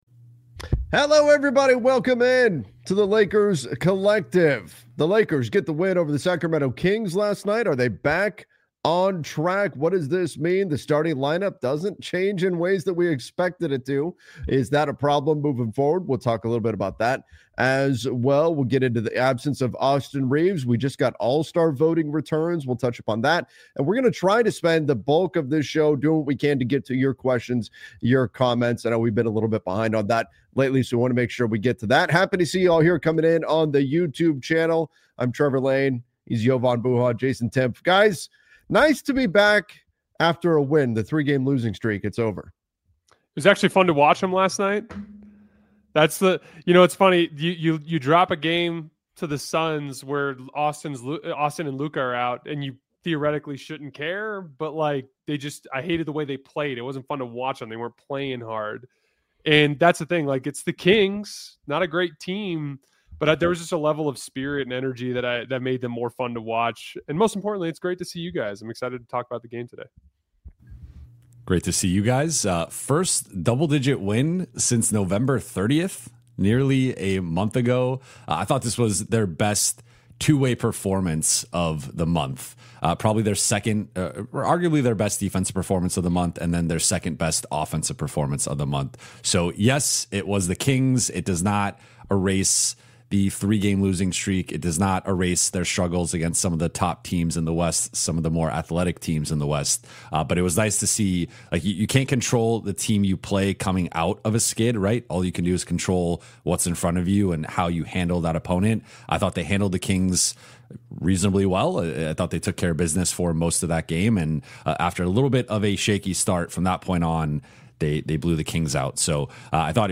a weekly Los Angeles Lakers roundtable
We go live every Thursday at 10:00 AM PT with sharp analysis, smart X’s-and-O’s talk, and honest conversations about all things Lakers — from game breakdowns and player development to cap moves and playoff paths.